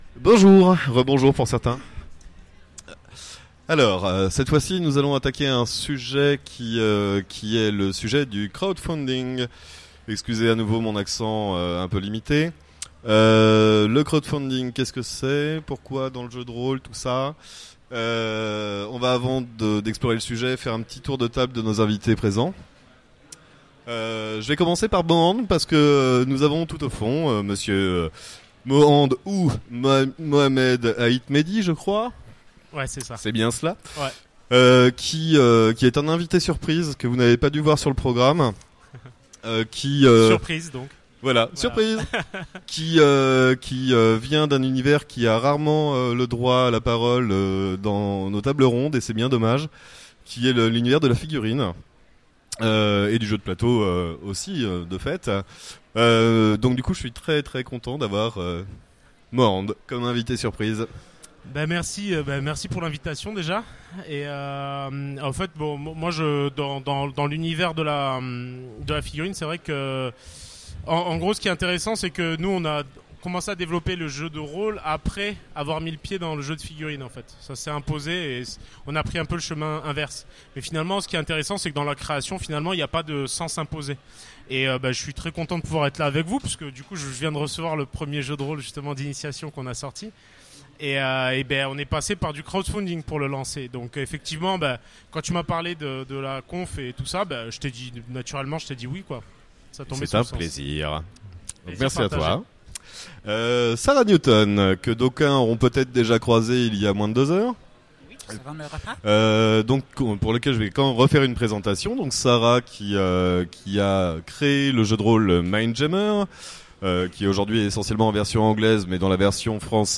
Utopiales 2016 : Conférence Le crowdfunding, une mutation de l’économie du jeu